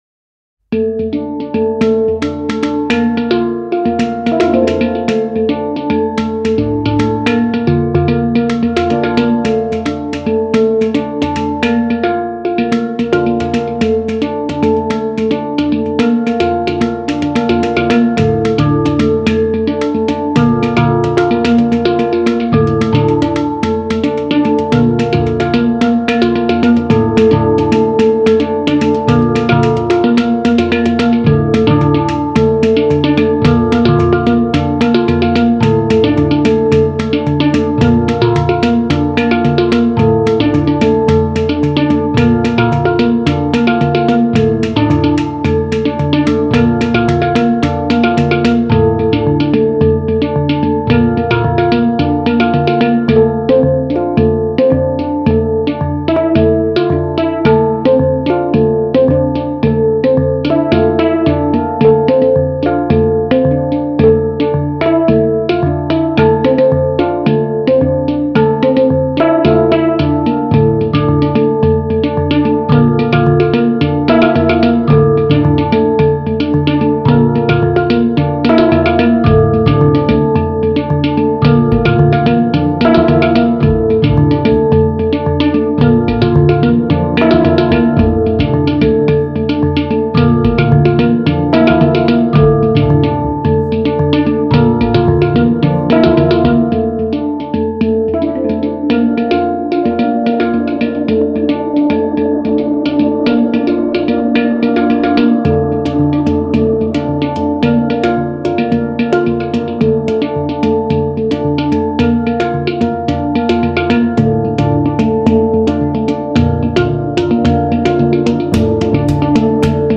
Largo [0-10] - - - -